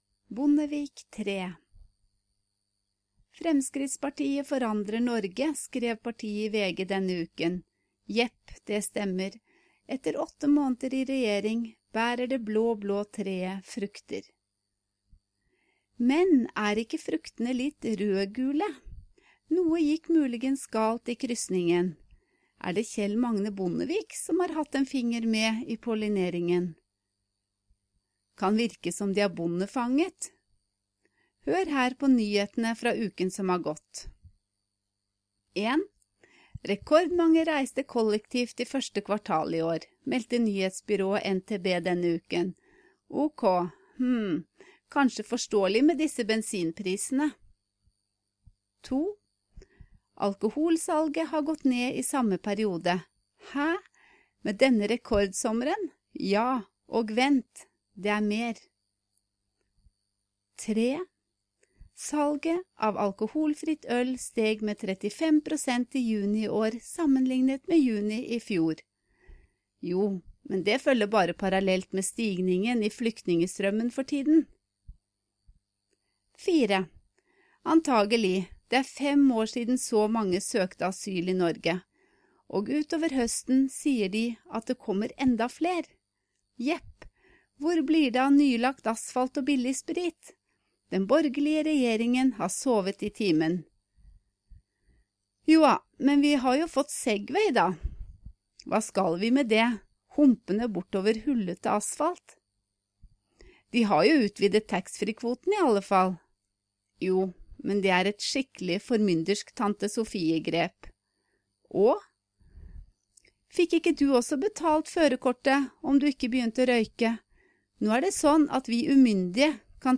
Learn Norwegian by listening to a native Norwegian while you’re reading along.